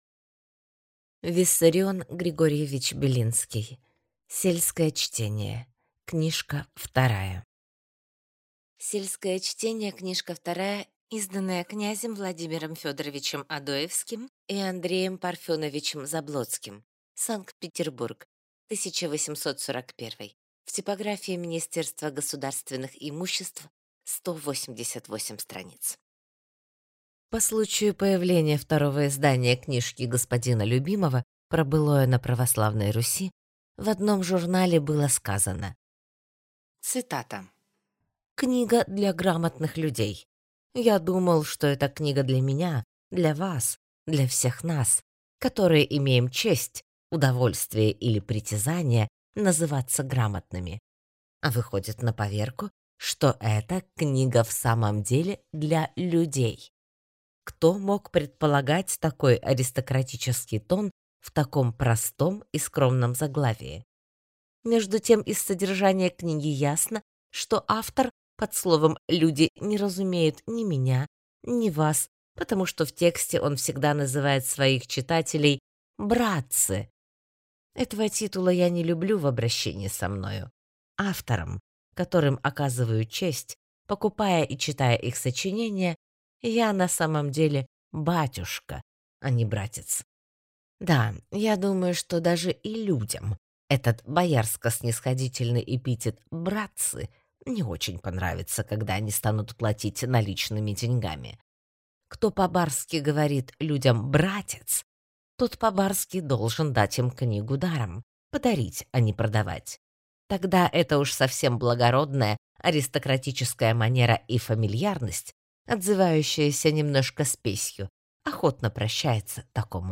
Аудиокнига Сельское чтение, книжка вторая…